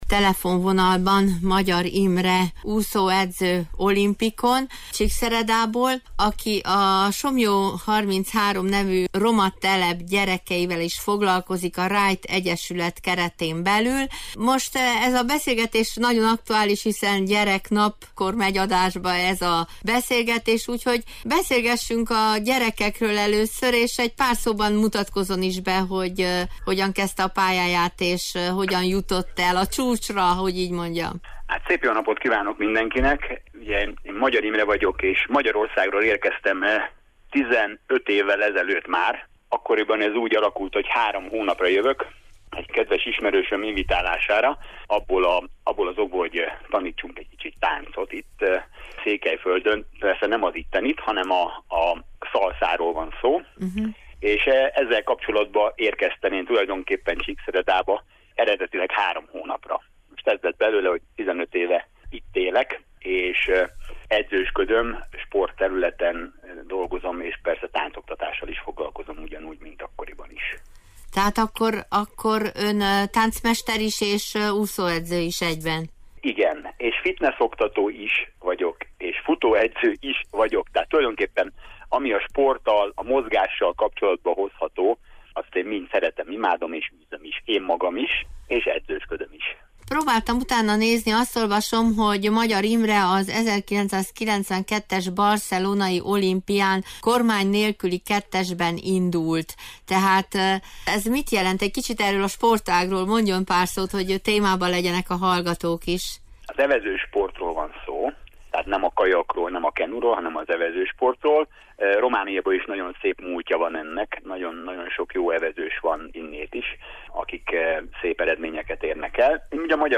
Ez az interjú hangzott el múlt vasárnapi műsorunkban. https